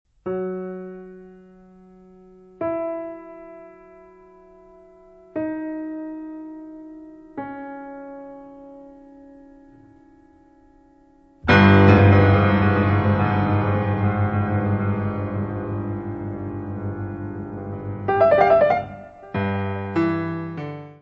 : stereo; 12 cm + folheto
Music Category/Genre:  New Musical Tendencies